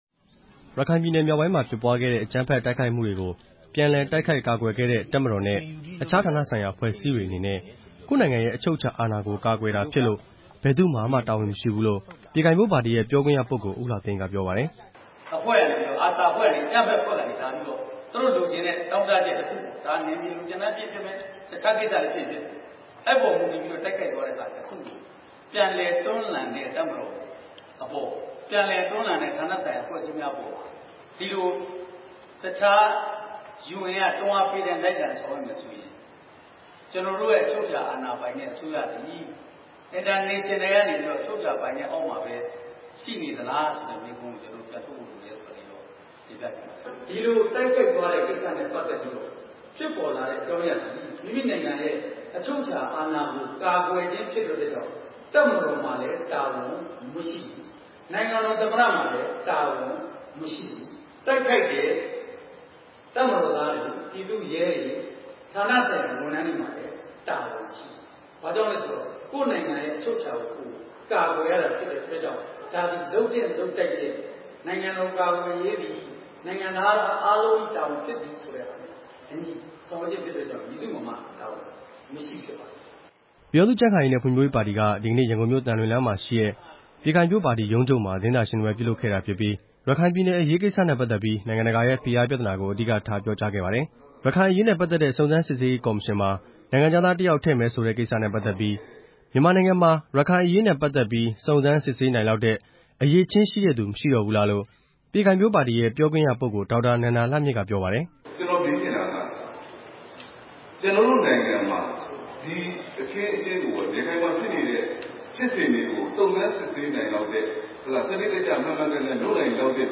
ရခိုင်ပြည်နယ်အရေး နဲ့ ကြားဖြတ်ရွေးကောက်ပွဲ အပေါ်သဘောထားနဲ့ပတ်သက်ပြီး ၂ဝ၁၈ ဇွန်လ ၂၉ ရက်နေ့မှာ ကြံ့ခိုင်ဖွံ့ဖြိုးရေးပါတီက သတင်းစာရှင်းလင်းပွဲ ပြုလုပ်ခဲ့ပါတယ်။